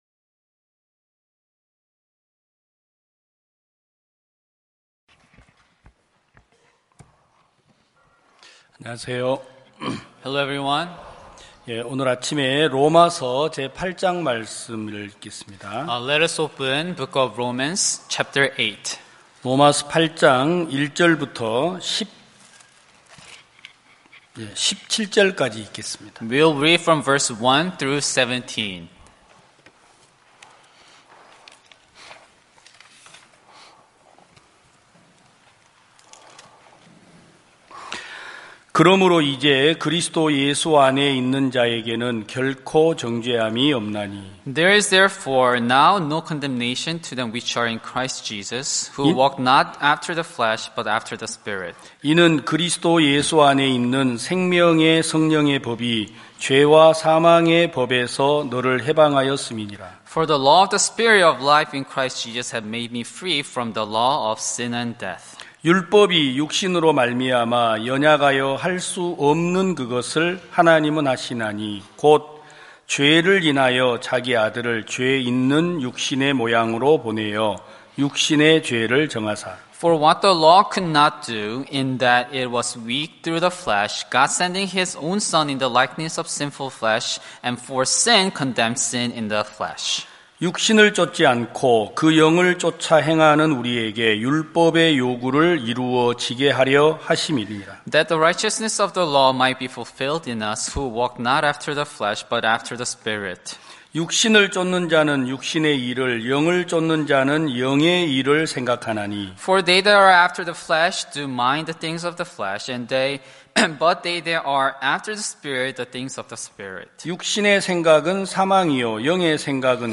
2021년 06월 13일 기쁜소식부산대연교회 주일오전예배
성도들이 모두 교회에 모여 말씀을 듣는 주일 예배의 설교는, 한 주간 우리 마음을 채웠던 생각을 내려두고 하나님의 말씀으로 가득 채우는 시간입니다.